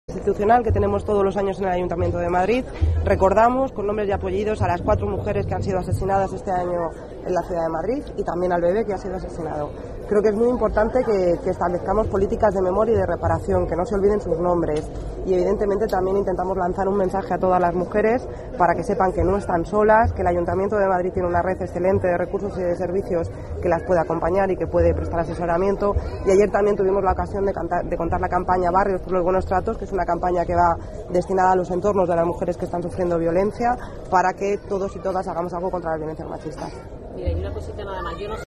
Nueva ventana:Declaraciones Celia Mayer en la intervención artística del túnel del Retiro